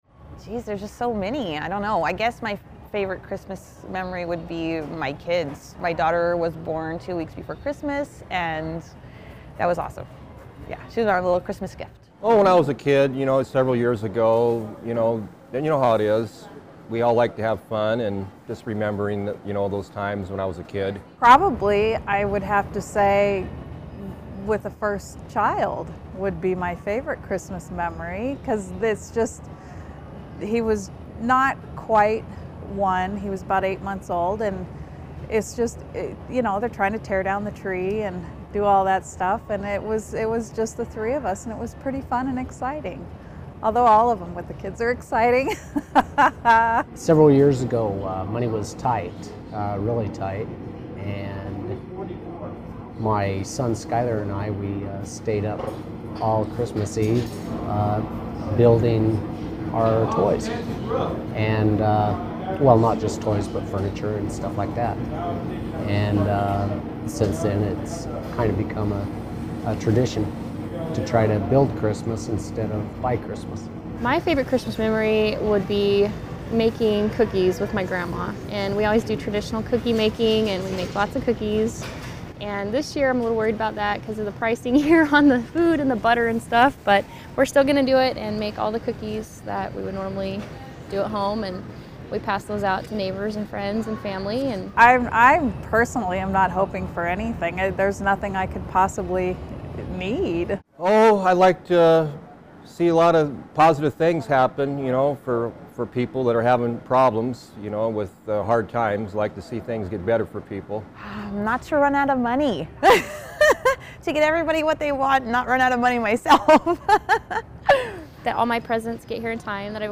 Channel 8 and KUCB spoke with community members about Christmas memories, Christmas wishes, the year in review, and the year to come.